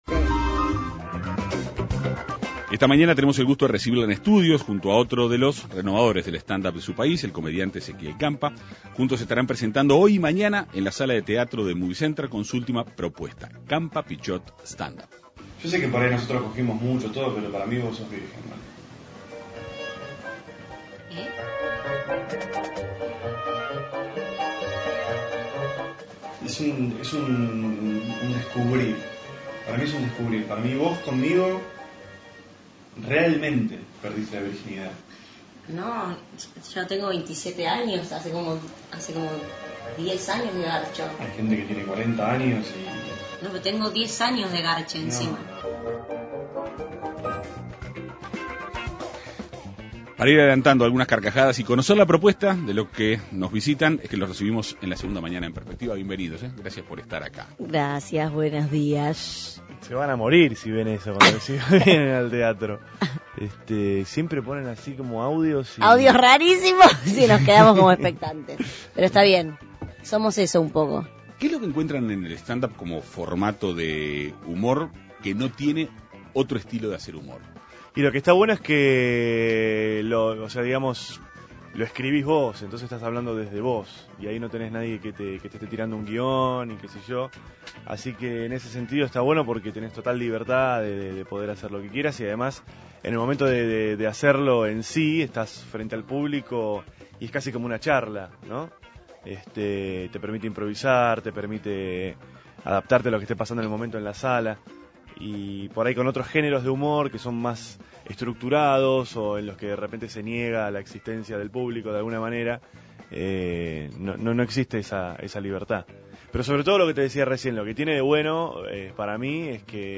Ambos dialogaron en la Segunda Mañana de En Perspectiva.